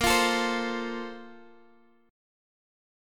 BbM7sus2 Chord